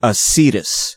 Uttal
IPA: /sʉːr/